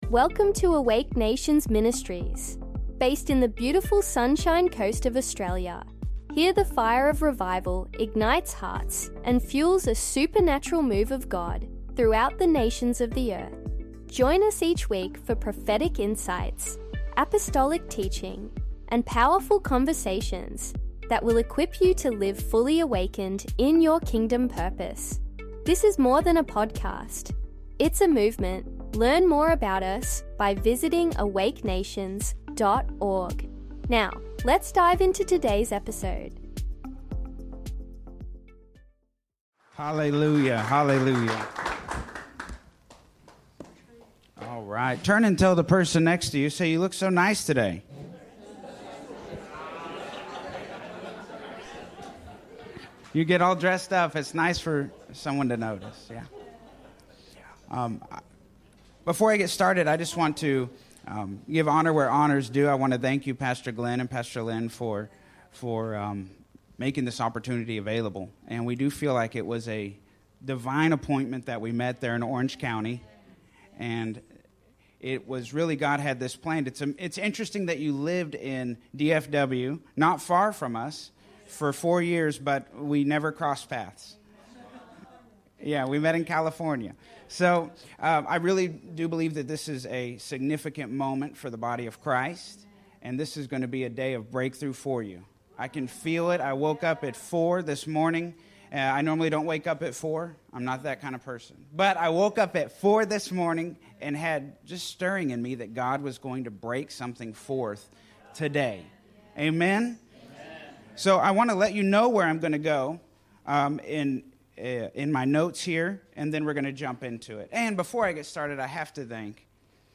In this message, you’ll discover how faith, obedience, and your giving ignite the release of heaven’s provision.